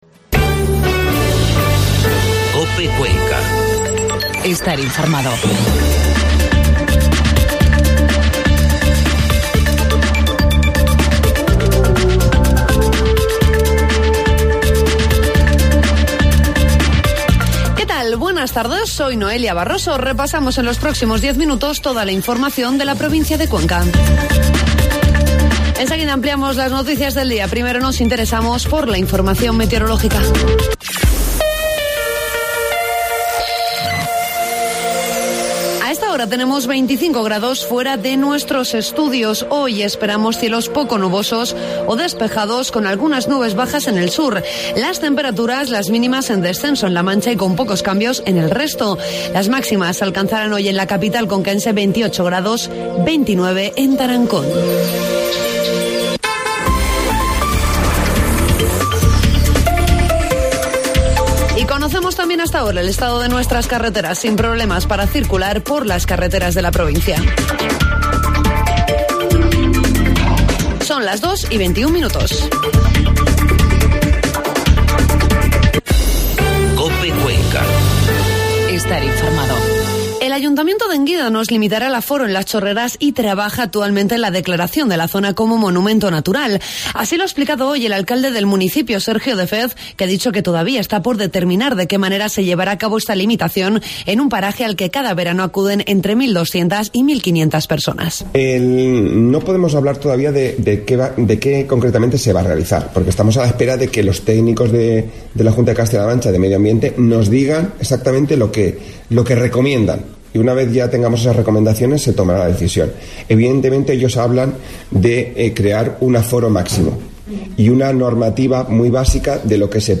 AUDIO: Informativo mediodía